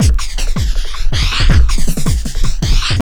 49JUNGL160.wav